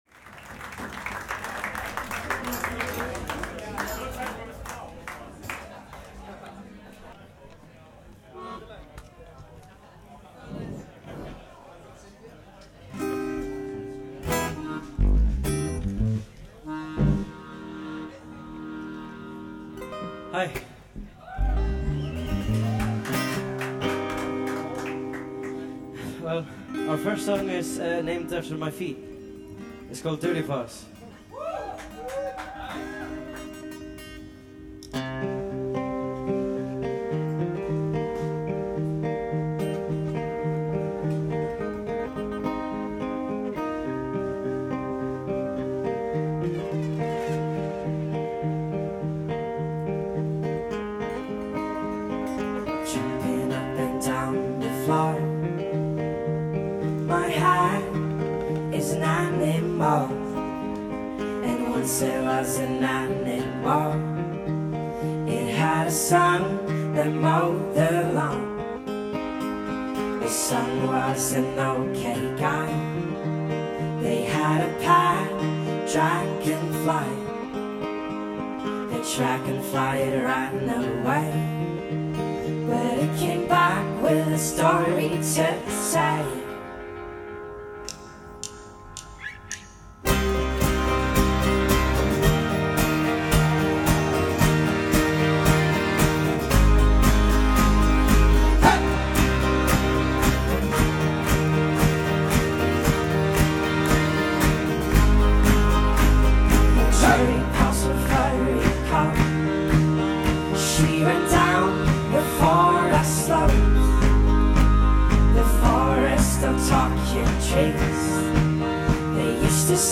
stripped-down/back-to-basics music